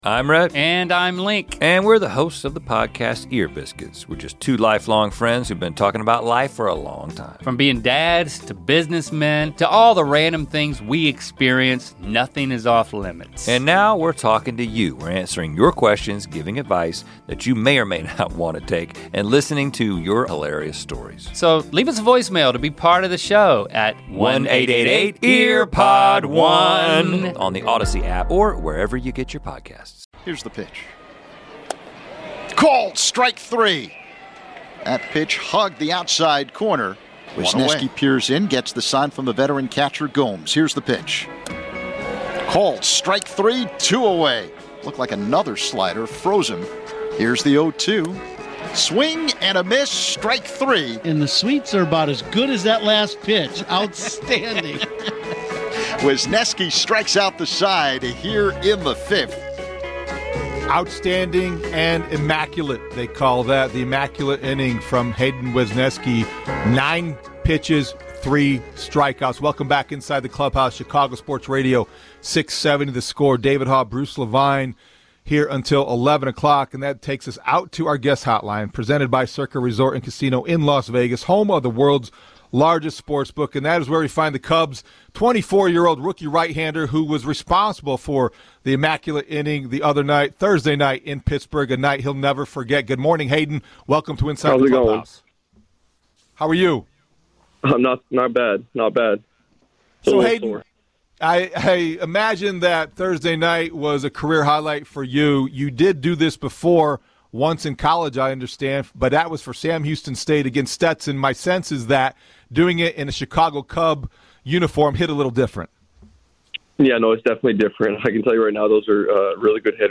Hayden Wesneski & Elvis Andrus interviews (Hour 2)